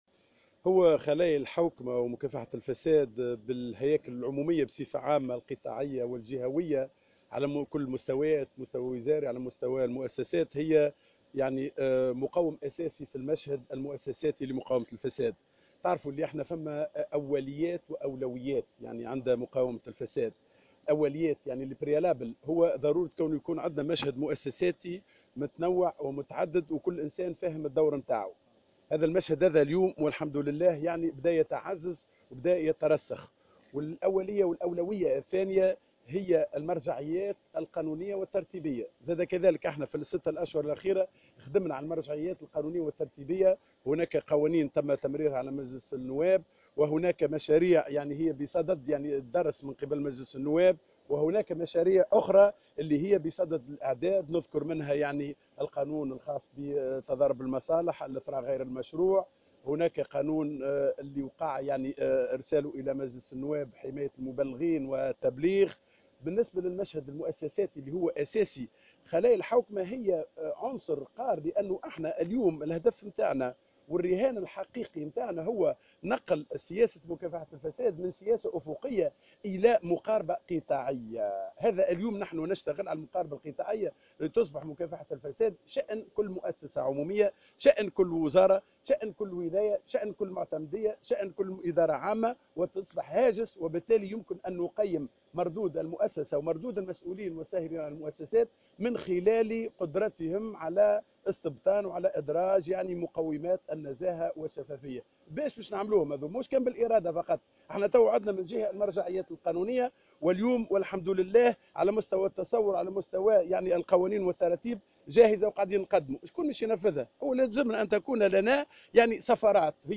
أكد وزير الحوكمة ومكافحة الفساد كمال العيادي في تصريح لمراسلة الجوهرة "اف ام" أن خلايا الحوكمة ومقاومة الفساد بالهياكل العمومية القطاعية والجهوية على كل المستويات هي مقوم أساسي في المشهد المؤسساتي لمقاومة الفساد على حد قوله.